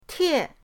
tie4.mp3